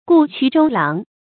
注音：ㄍㄨˋ ㄑㄩˇ ㄓㄡ ㄌㄤˊ
顧曲周郎的讀法